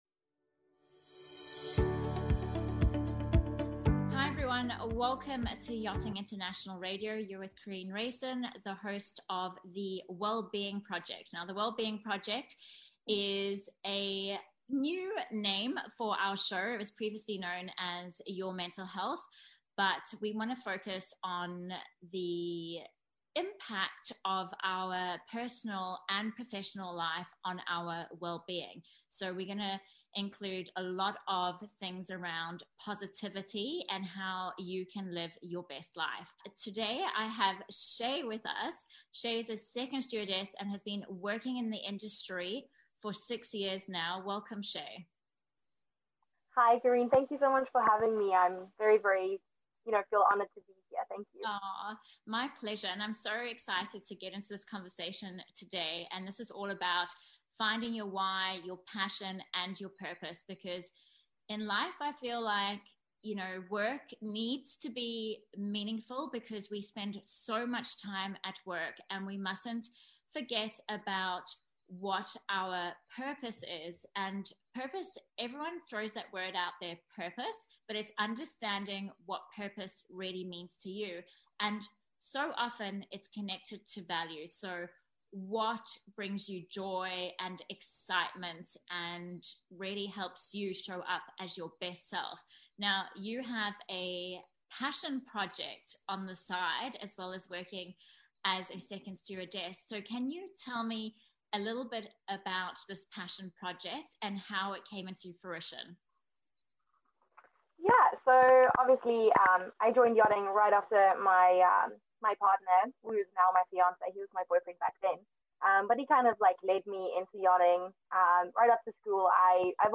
Welcome to our new interview series for this month which is geared to help you discover your purpose in your career now or in the future.